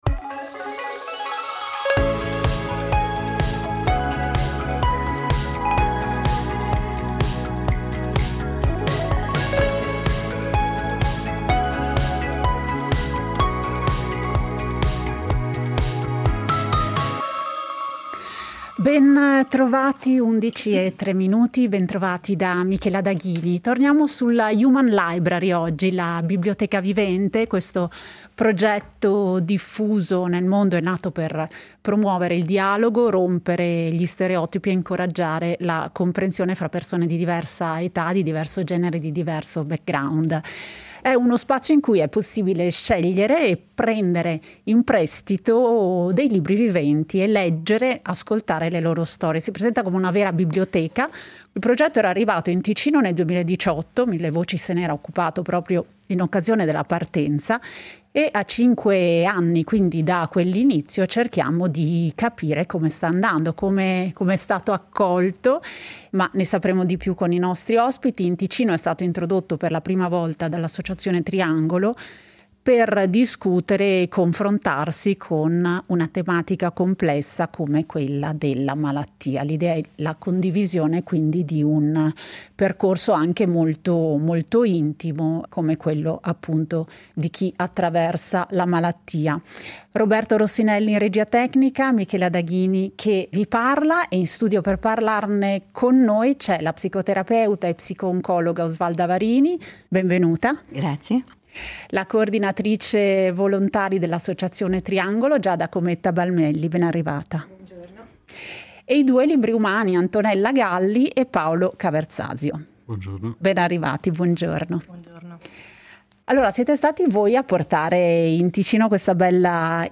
In studio per parlarne